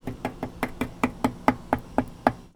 R - Foley 122.wav